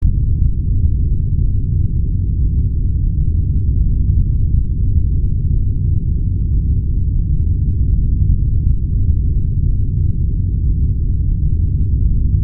Атмосферы звуки скачать, слушать онлайн ✔в хорошем качестве